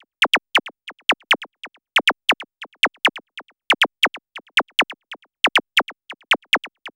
101 ZAP DE-L.wav